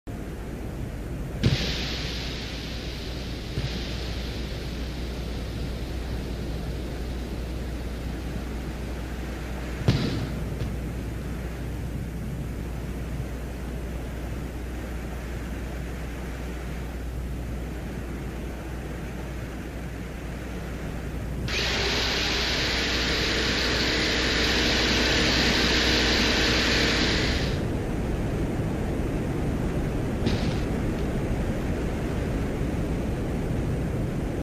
But another incredible fact is that Venera 13 and 14 were equipped with microphones and provided the first ever audio recordings from the surface of another planet!
The spacecraft recorded the landing, the removal of the camera lens cap, the drilling into the surface of Venus, and the deposition of the sampled soil into a chamber inside the spacecraft